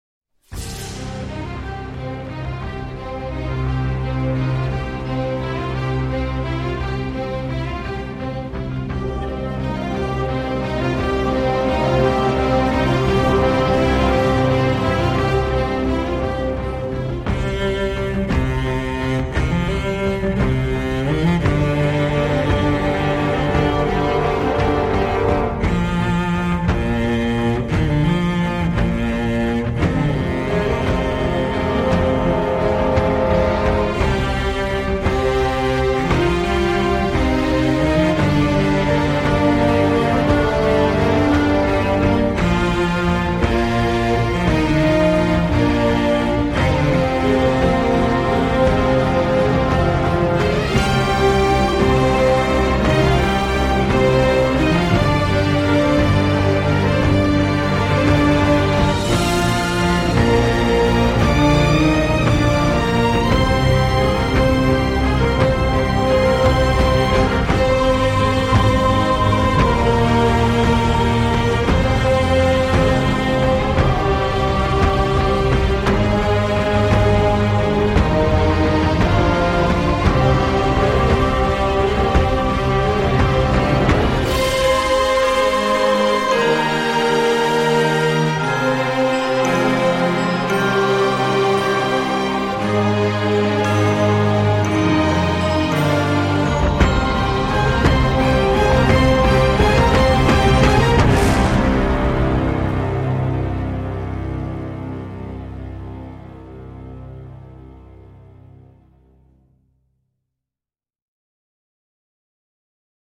其中的配乐非常震撼，无与伦比。
王者之气，霸气，威武，神圣不可侵犯！低沉的大提琴配合的太到位了。大提琴音域宽广并且富于感情，能表达深沉和忧郁的情绪。
尤其是片头的主题音乐大提琴贯穿始终，气吞山河，成就了影史上最为夺目的片头。